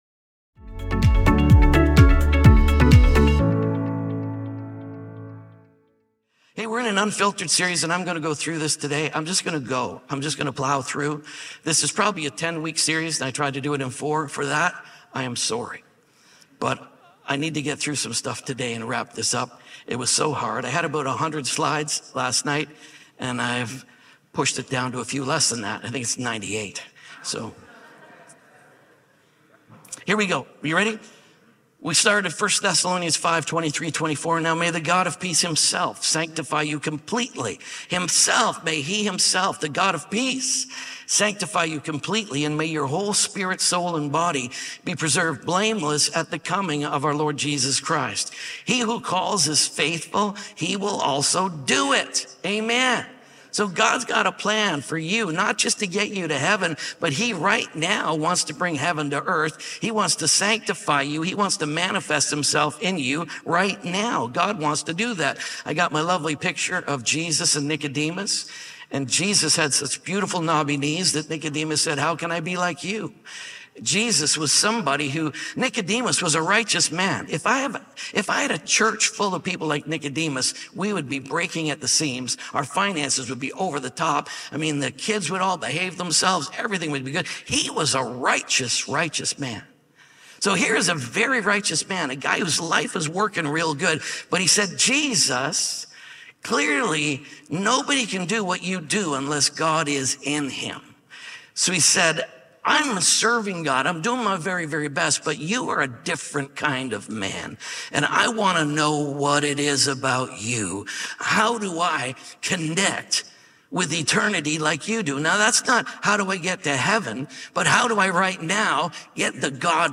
Soul Prosperity | UNFILTERED | SERMON ONLY.mp3